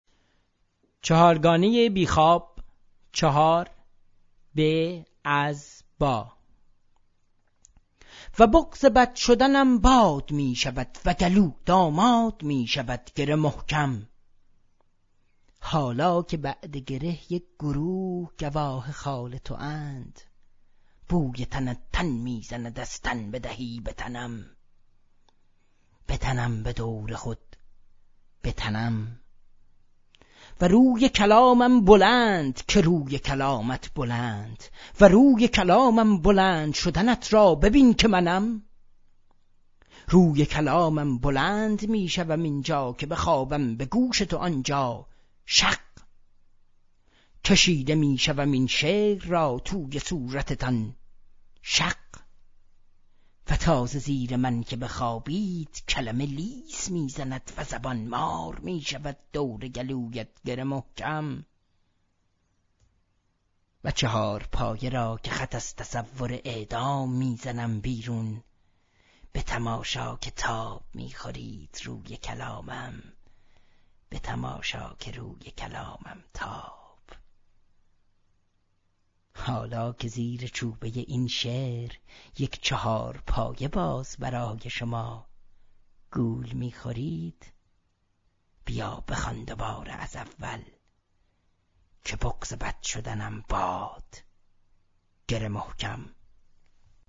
صدای شاعر